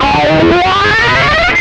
MANIC GLISS2.wav